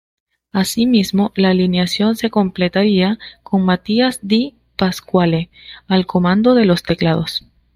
Read more Noun Verb comandar to lead Read more Frequency C1 Hyphenated as co‧man‧do Pronounced as (IPA) /koˈmando/ Etymology Deverbal from comandar.